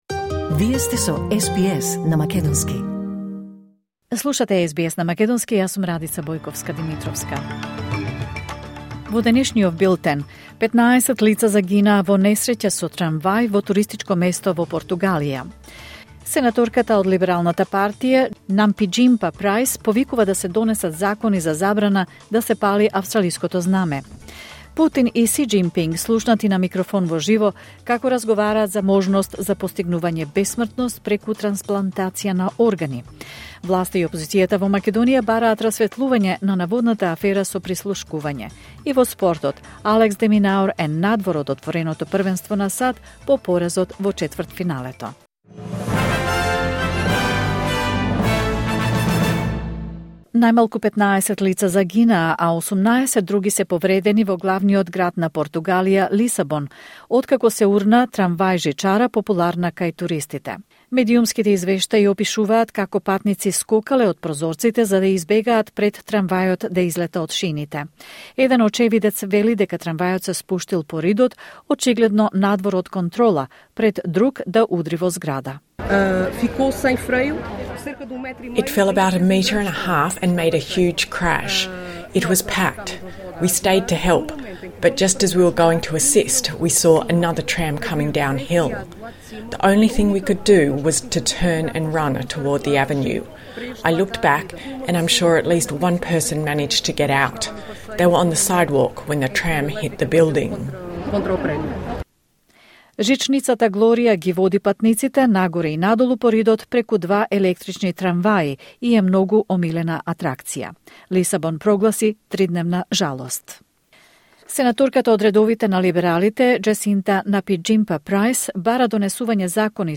Вести на СБС на македонски 4 септември 2025